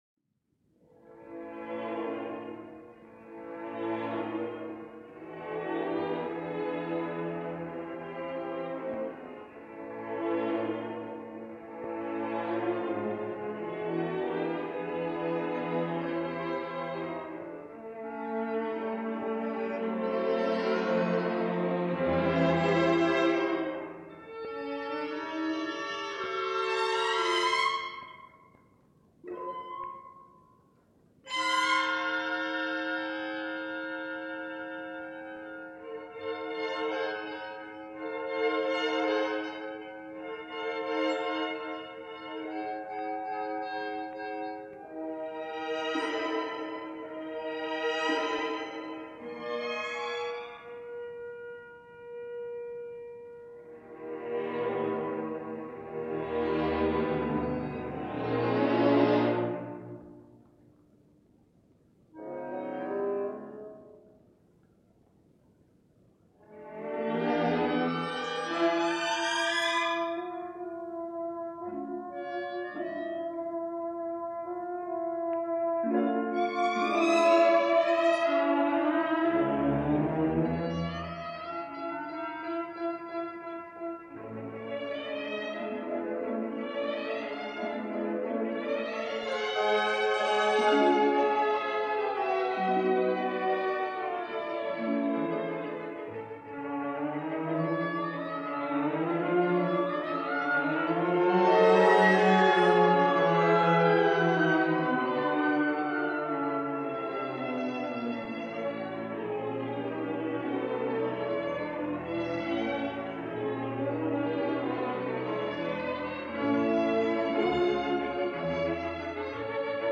Les Eolides was one of his earliest symphonic works.